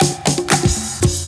DRUMFILL11-R.wav